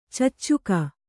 ♪ caccuka